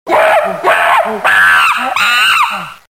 monkeySnd.mp3